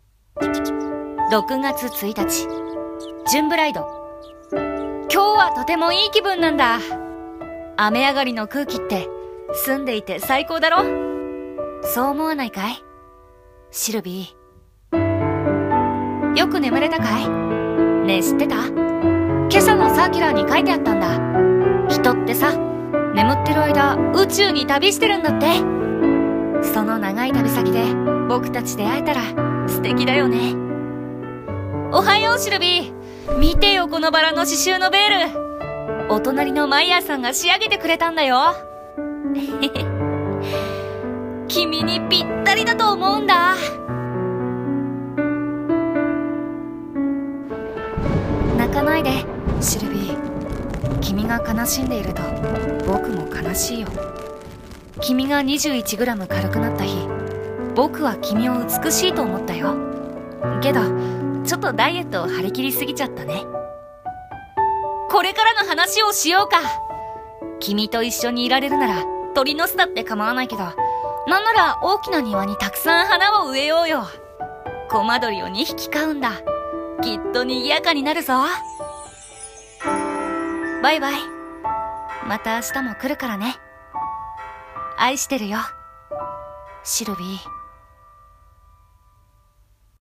CM風声劇「シルヴィ」お手本